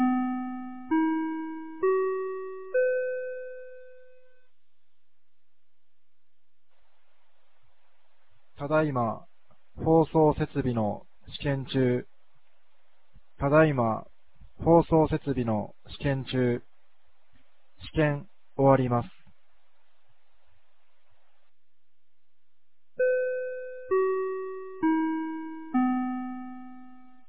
2025年09月06日 16時02分に、由良町から全地区へ放送がありました。
放送音声